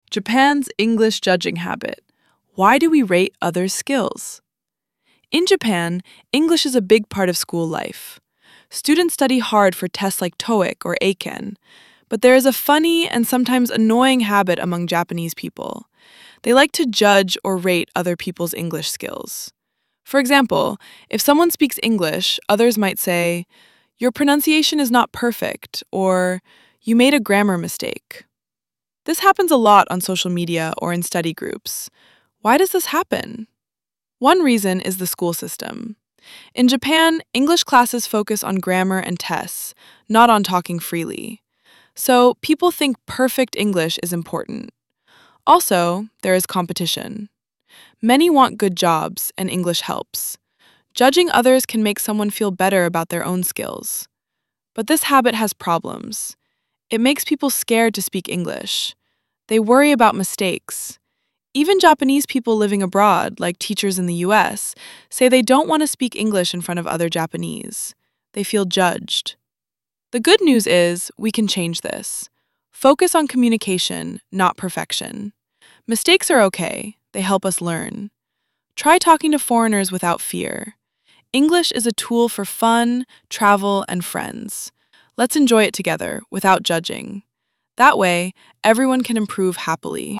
＜音読用音声＞